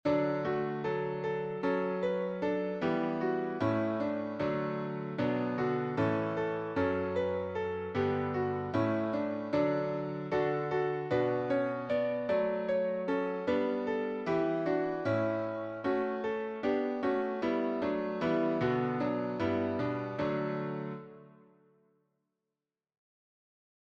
Solesmes Version of the Plainsong Melody